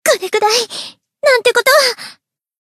贡献 ） 分类:蔚蓝档案语音 协议:Copyright 您不可以覆盖此文件。
BA_V_Mimori_Battle_Damage_3.ogg